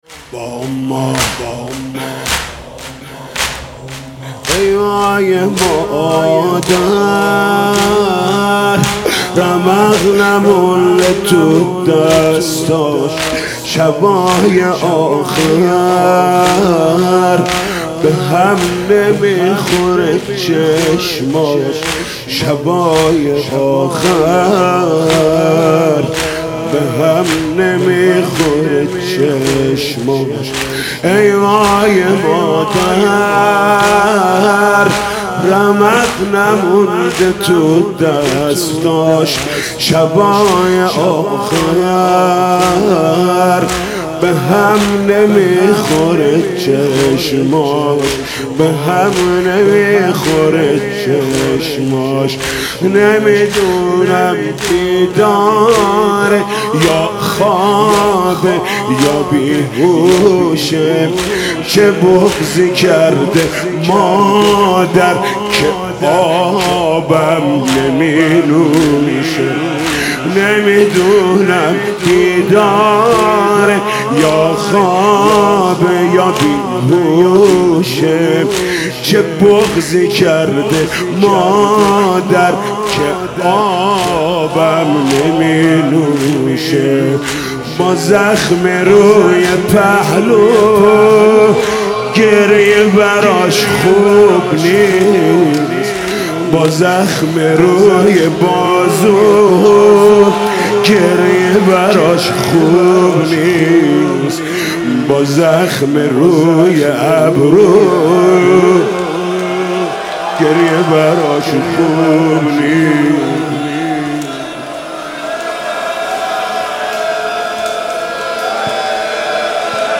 «فاطمیه 1396» زمینه: ای وای مادر رمق نمونده تو دستاش